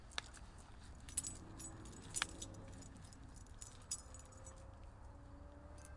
遛狗叮当
描述：一只狗在散步，而他的项圈在叮当响
标签： 狗项圈 狗标签 狗散步
声道立体声